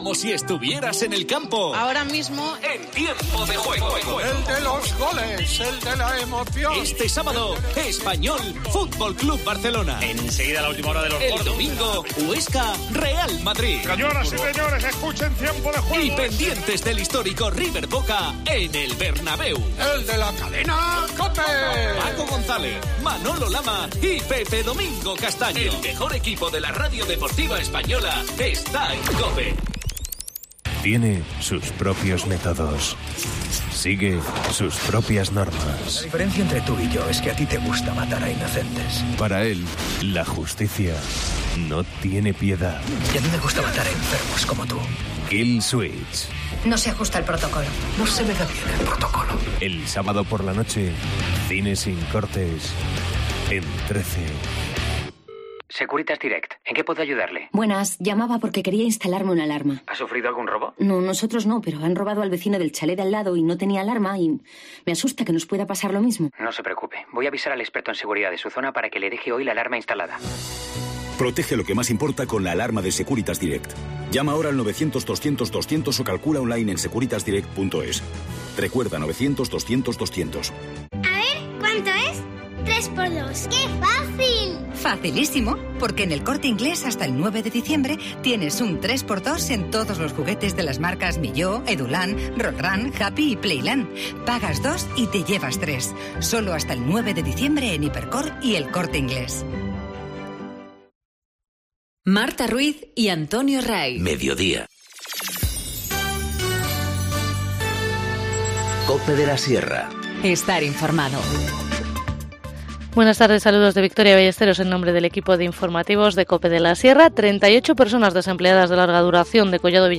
Informativo Mediodía 7 dic- 14:20h